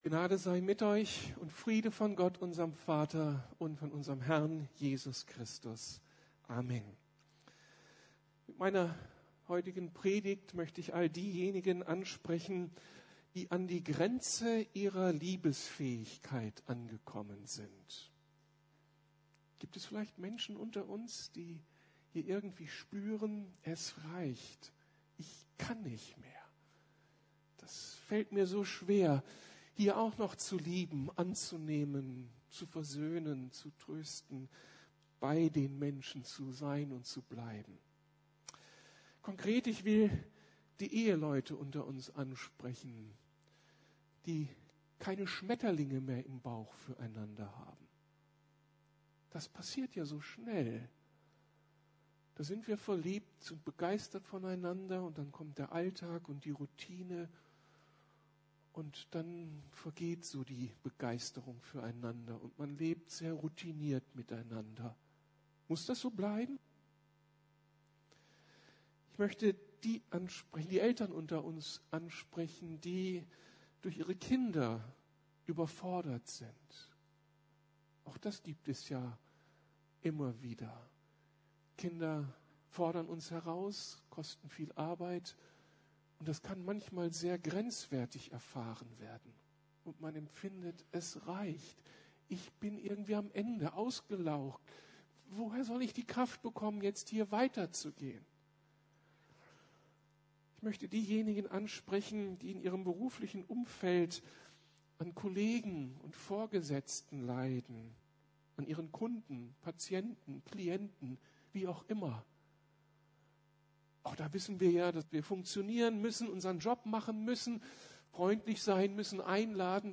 Komm mit zur "Liebesquelle" ~ Predigten der LUKAS GEMEINDE Podcast